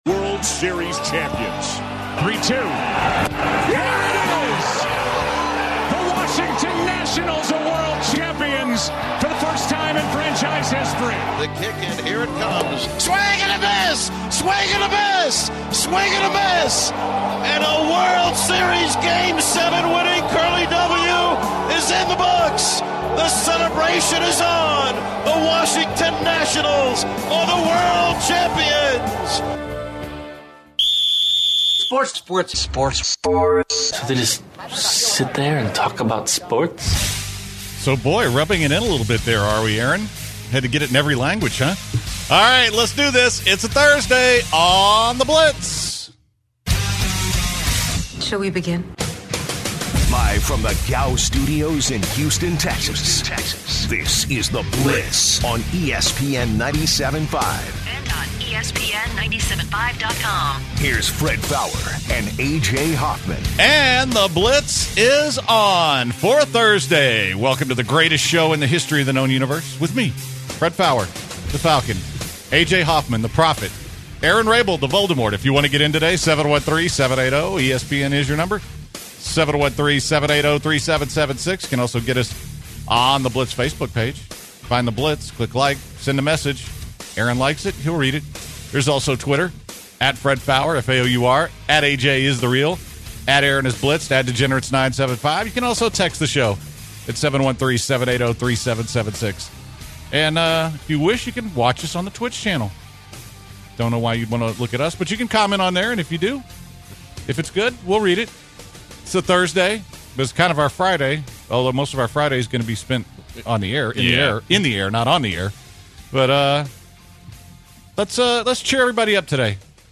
The hot topic early on is about A.J. Hinch’s decision to take out Zach Greinke late in the game. Then the guys continue to discuss the game and the series with callers.